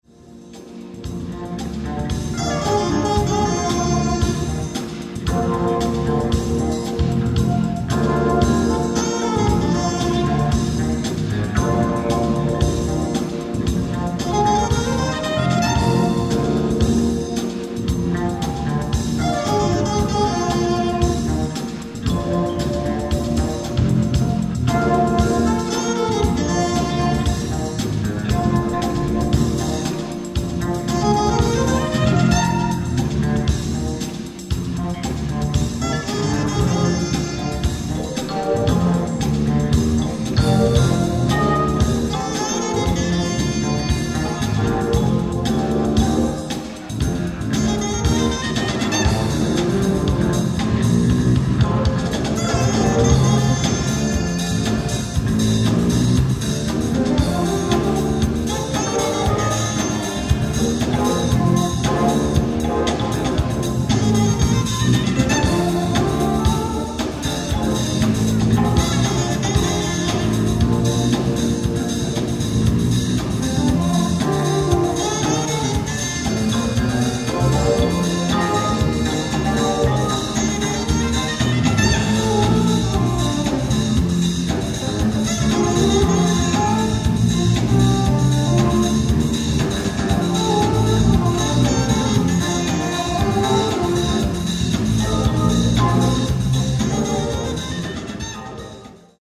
ライブ・アット・ベテランズ・メモリアル・オーディトリアム、プロビデンス、ロードアイランド 11/12/1978
衝撃のオーディエンス音源！！
※試聴用に実際より音質を落としています。